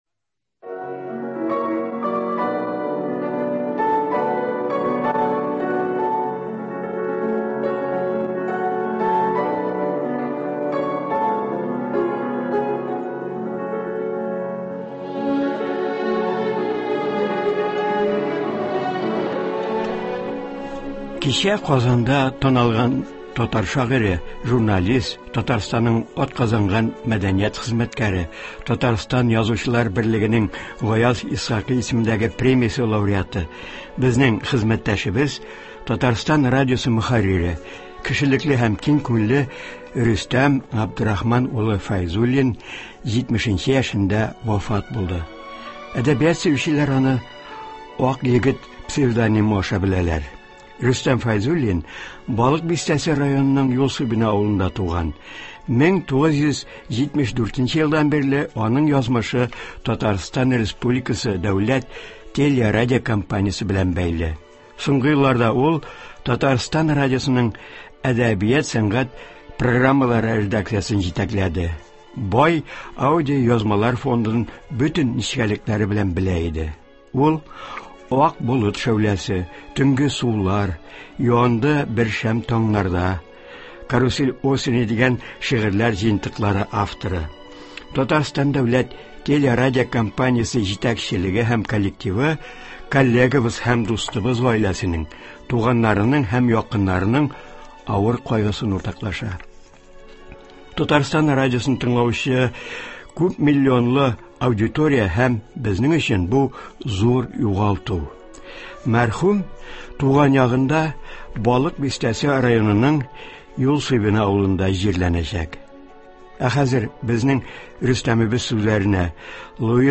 Бу сәхифәдә Рөстәм Фәйзуллинның иҗат юлы турында мәгълүмат бирелә, аның сүзләренә язылган җыр яңгырый.